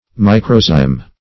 Search Result for " microzyme" : The Collaborative International Dictionary of English v.0.48: Microzyme \Mi"cro*zyme\, n. [Micro- + Gr. zy`mh leaven.]